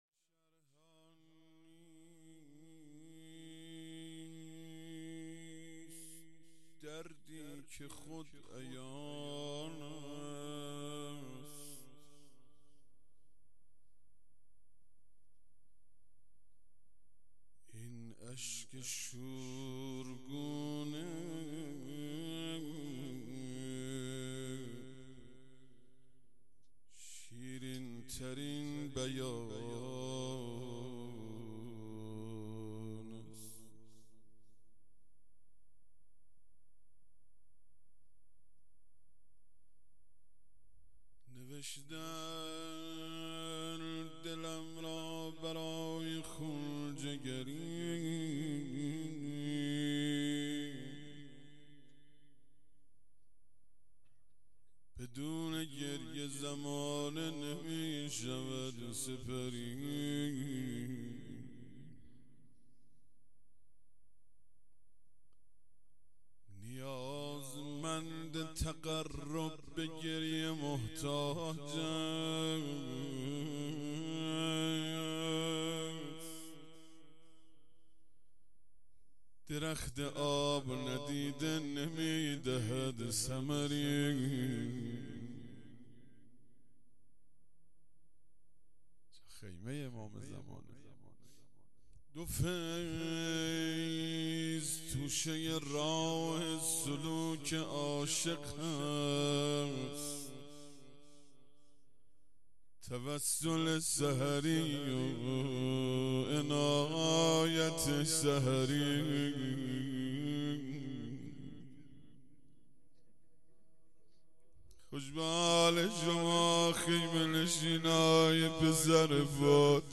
مراسم چهلم سردار قاسم سلیمانی